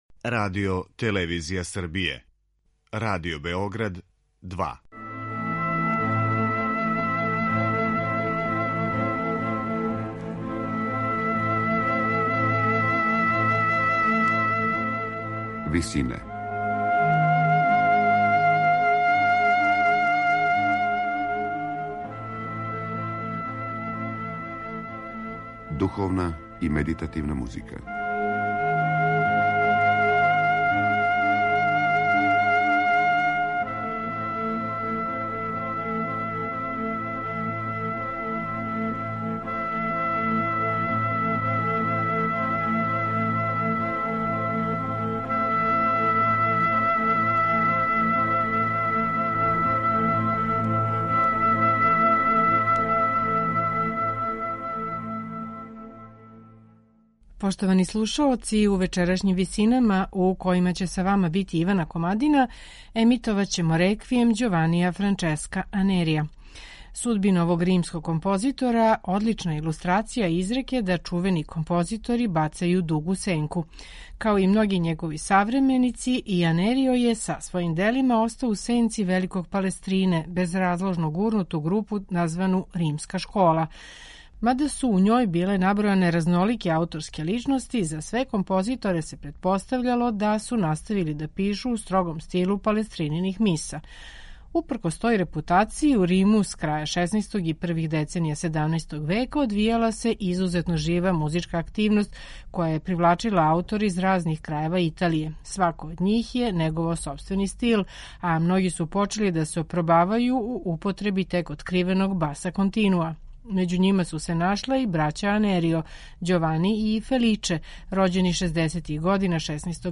На крају програма, у ВИСИНАМА представљамо медитативне и духовне композиције аутора свих конфесија и епоха.
Оно по чему је Анериово остварење особено јесте неуобичајен полифони третман секвенце „Dies irae", од које је аутор направио централно место читавог дела, користећи уједно бројне могућности тонског сликања сваке речи. У вечерашњим Висинама Реквијем Ђованија Франческа Анерија слушамо у интерпретацији хора Вестминстерске катедрале, под управом Џејмса О'Донела.